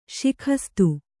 ♪ śikhastu